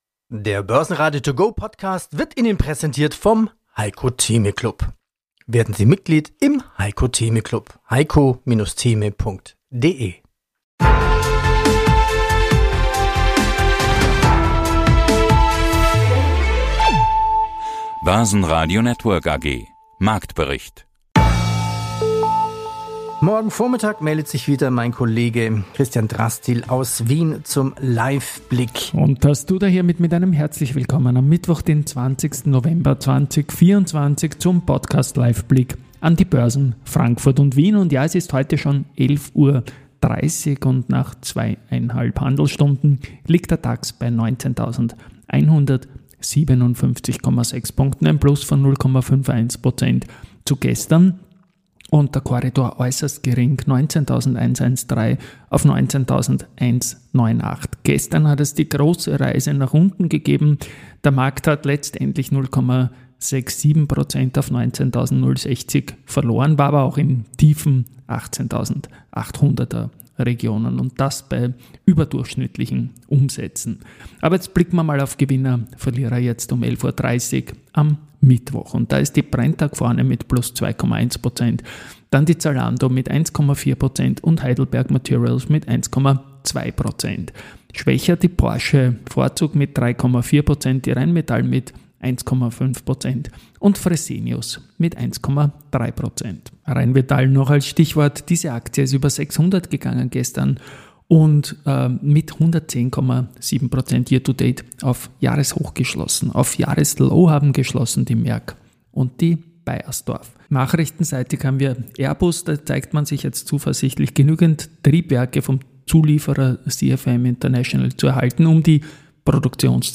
Die Börse zum hören: mit Vorstandsinterviews, Expertenmeinungen und Marktberichten.
Leistungen zählen neben Interviews mit Experten, Analysten,